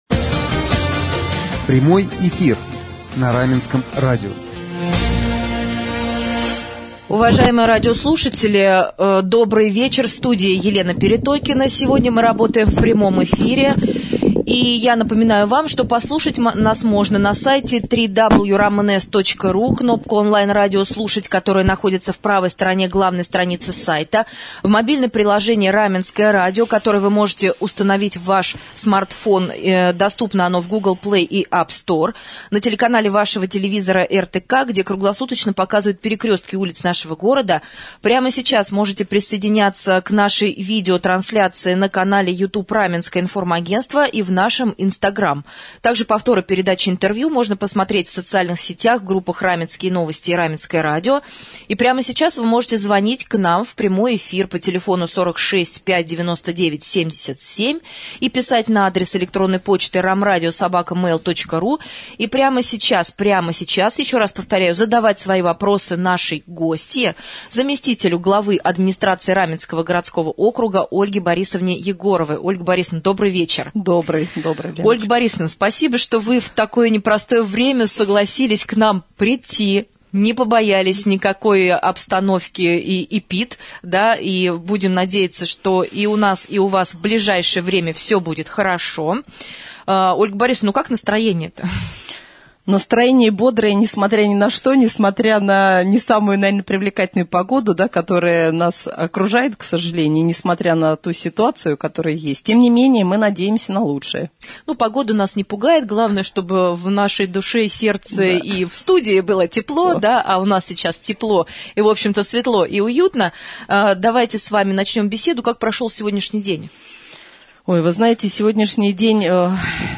21 октября гостем вечернего прямого эфира стала заместитель главы администрации Раменского г.о. Ольга Борисовна Егорова.